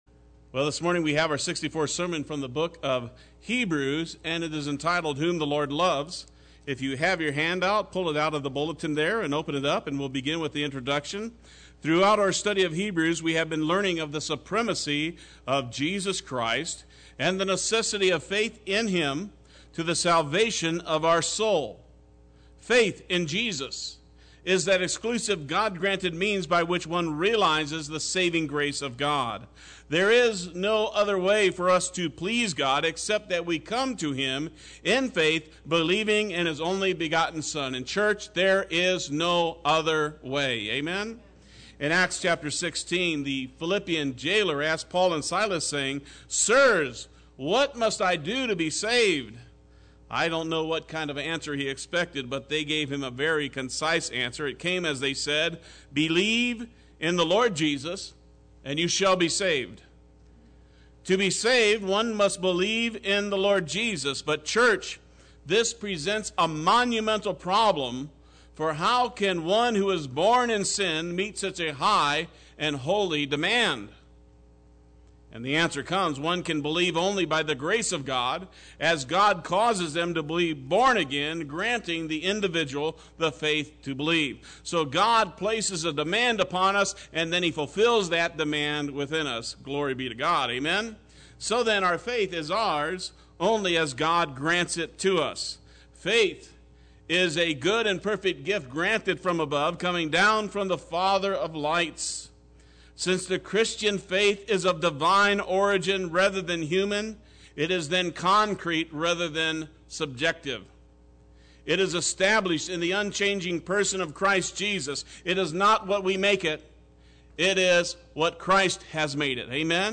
Play Sermon Get HCF Teaching Automatically.
Whom the Lord Loves Sunday Worship